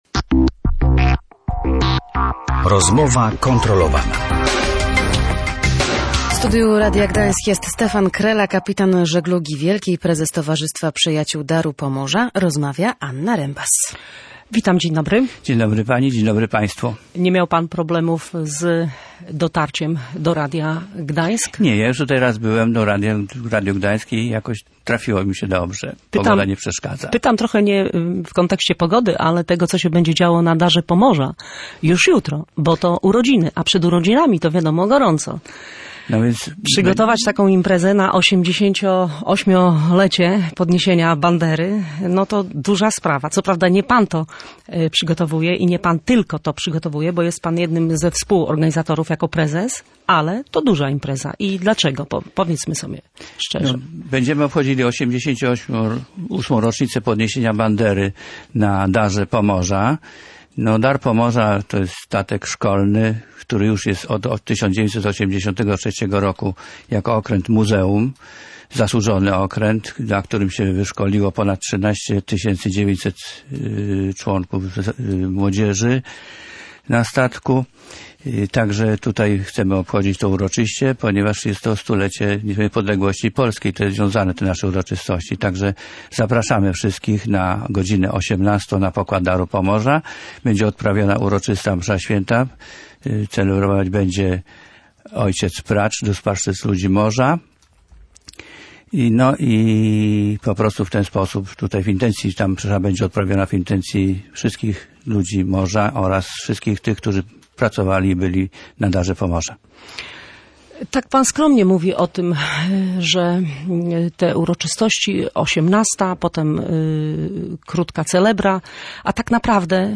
Kapitan żeglugi wielkiej przypomina jego historię